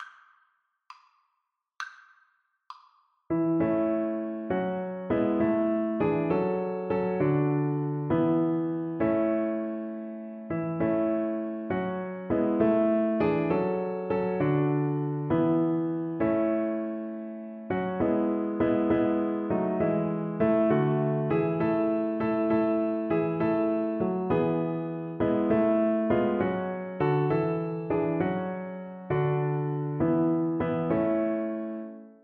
Violin
6/8 (View more 6/8 Music)
A major (Sounding Pitch) (View more A major Music for Violin )
Christmas (View more Christmas Violin Music)